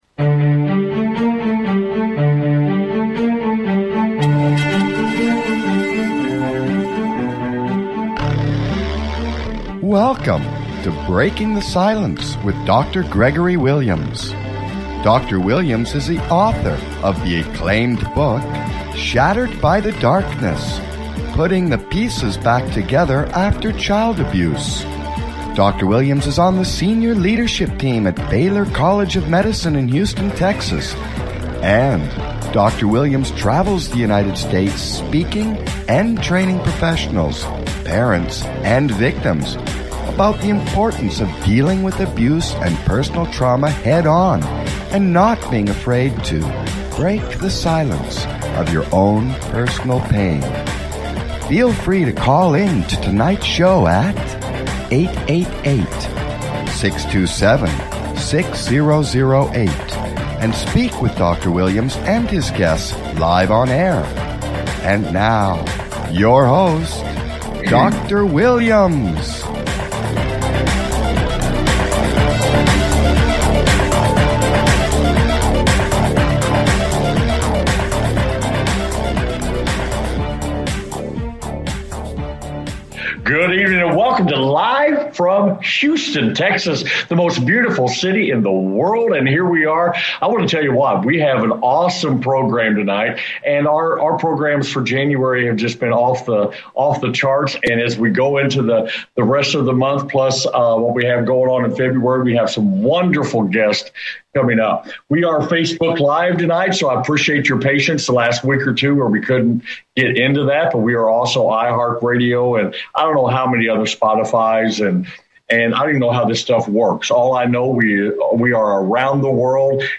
Guest, Dr Gail Dines, professor emerita of sociology and women's studies at Wheelock College in Boston, Massachusetts.